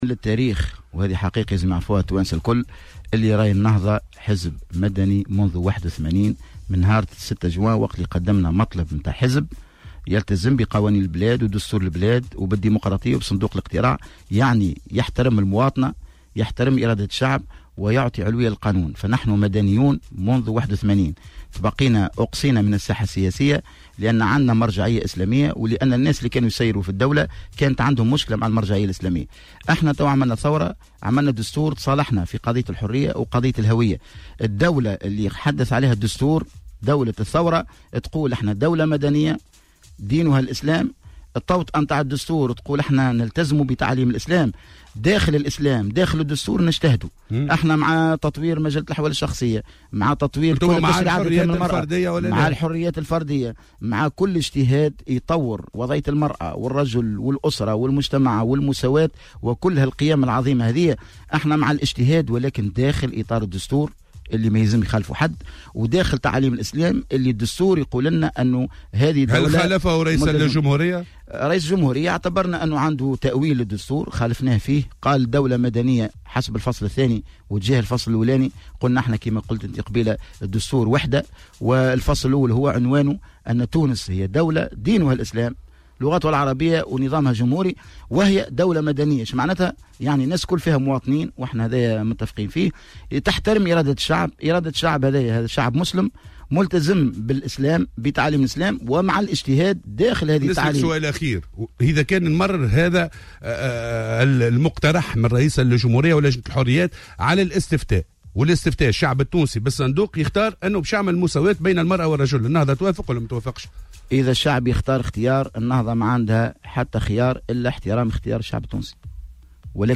قال رئيس مجلس شورى حركة النهضة، عبد الكريم الهاروني في مداخلة له اليوم في برنامج "صباح الورد" على "الجوهرة أف أم" إن " حركة النهضة حزب مدني منذ سنة 1981 وإنها تحترم إرادة الشعب وعلوية القانون، لكن تم استبعادها في السابق من الساحة السياسية بسبب المرجعية الإسلامية".